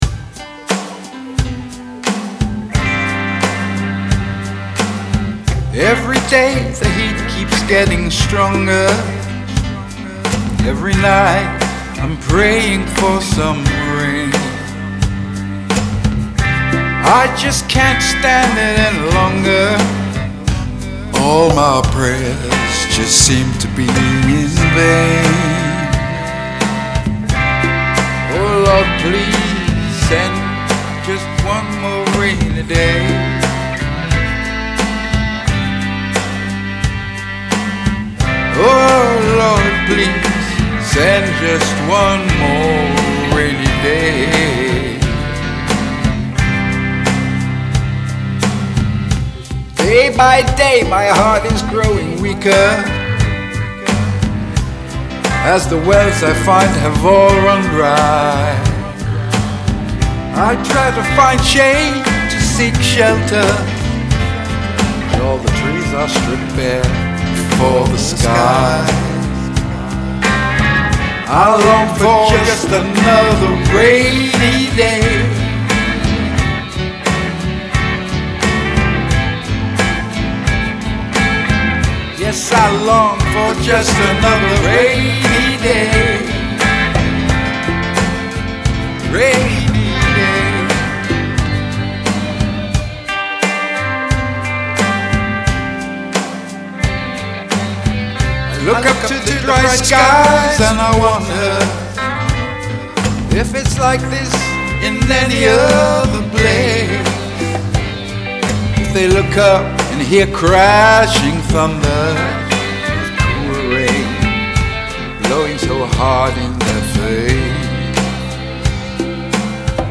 Rock songs